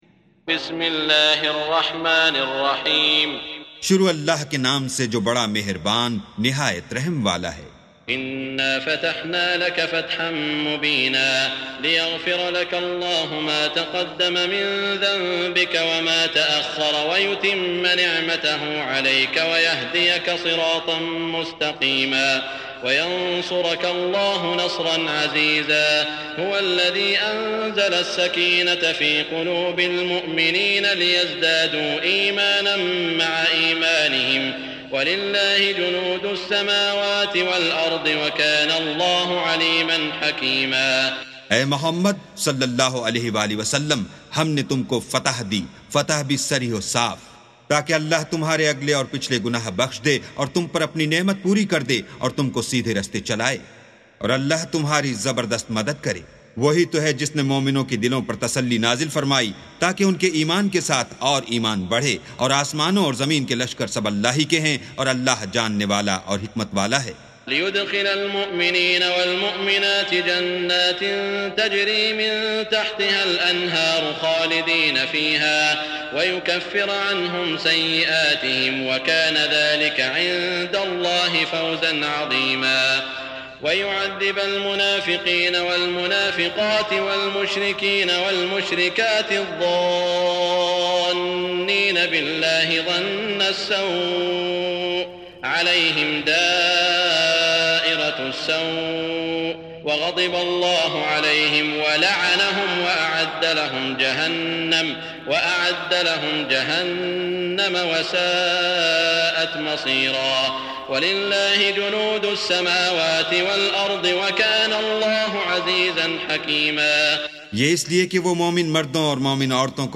سُورَةُ الفَتۡحِ بصوت الشيخ السديس والشريم مترجم إلى الاردو